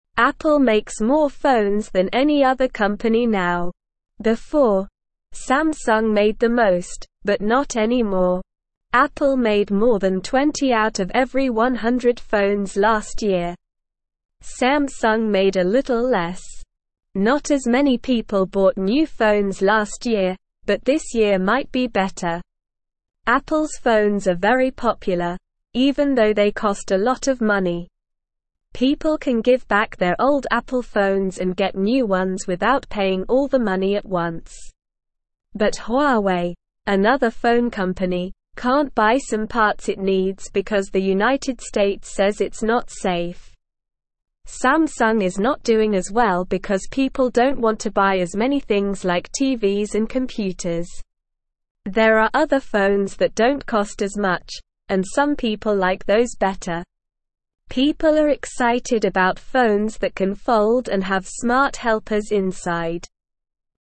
Slow
English-Newsroom-Beginner-SLOW-Reading-Apple-Makes-the-Most-Phones-Samsung-Not-Doing-Well.mp3